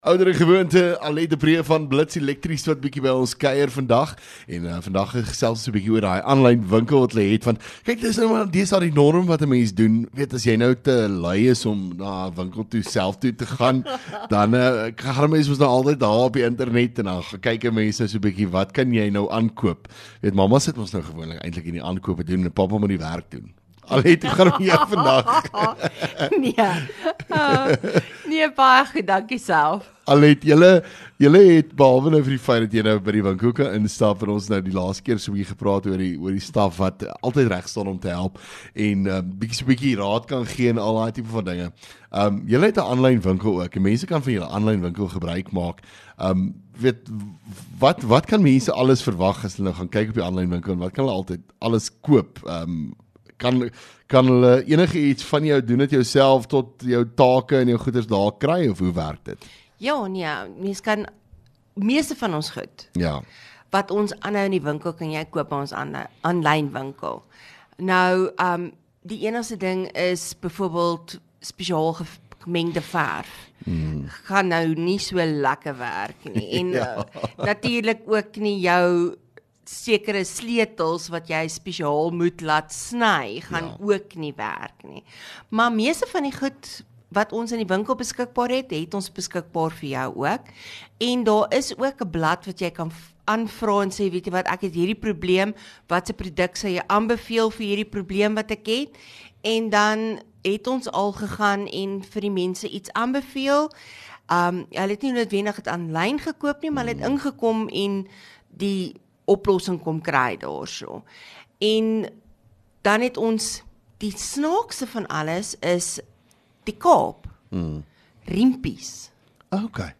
LEKKER FM | Onderhoude 20 Feb Blits Elektrisiëns